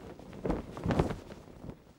cloth_sail4.R.wav